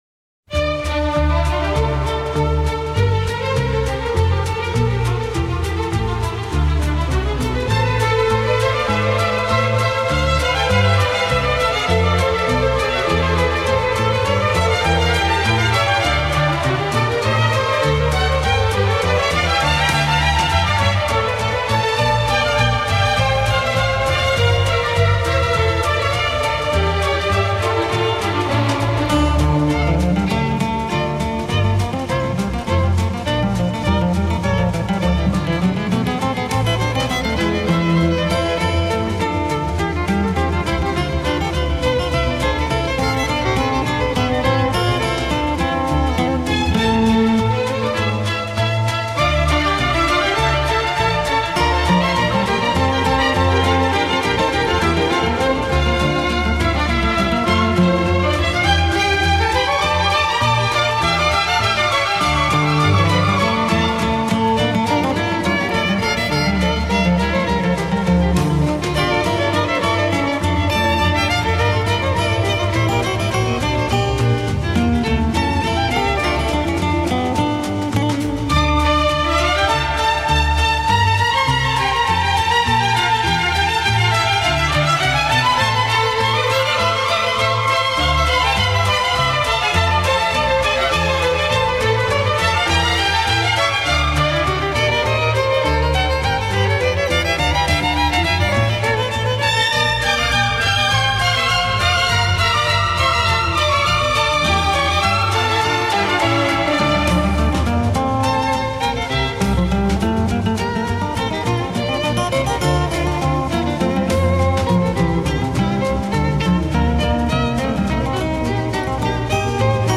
кто автор этой камерной музыки и пока не узнал.